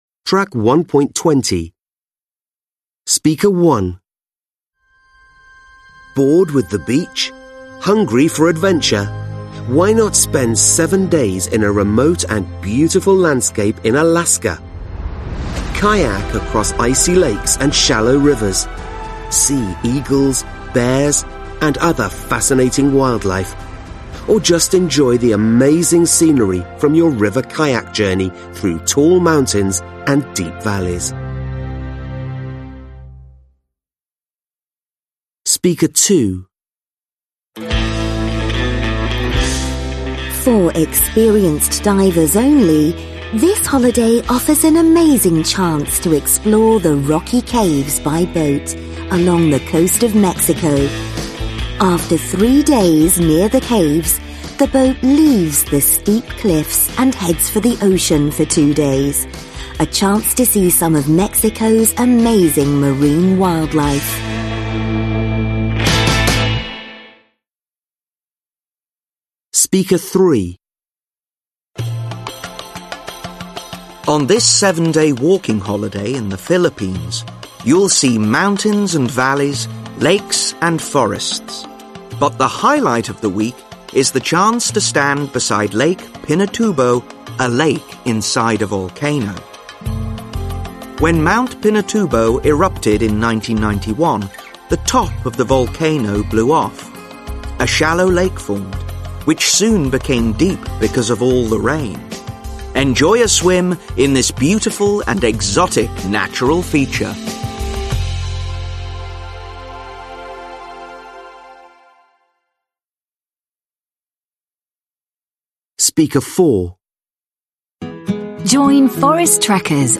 5 (trang 23 Tiếng Anh 10 Friends Global) Listen to four adverts. Match three of them with photos A – E. (Nghe bốn quảng cáo, ghép ba trong số chúng với ảnh A – E)